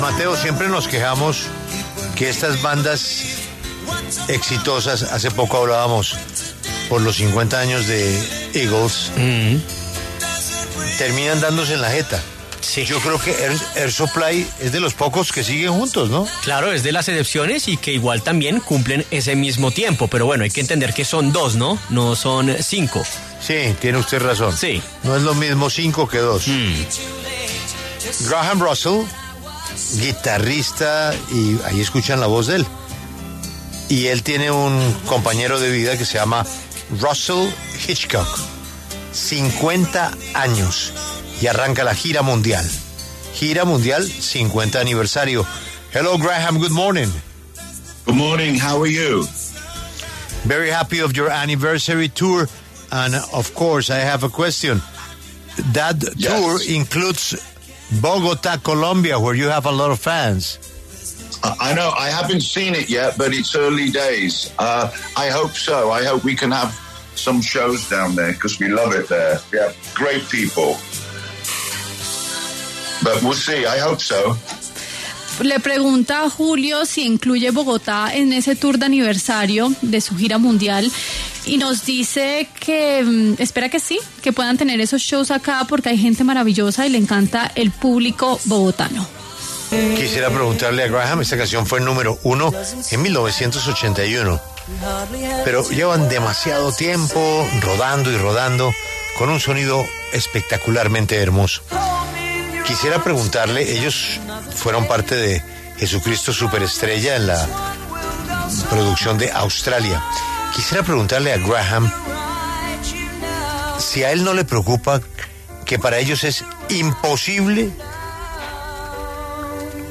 El guitarrista Graham Russell pasó por los micrófonos de La W con Julio Sánchez Cristo para compartir datos curiosos sobre su carrera musical y afirmó estar emocionado ante la idea de poder visitar Colombia.